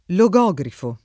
vai all'elenco alfabetico delle voci ingrandisci il carattere 100% rimpicciolisci il carattere stampa invia tramite posta elettronica codividi su Facebook logogrifo [ lo g o g r & fo , meglio che lo g0g rifo ] s. m.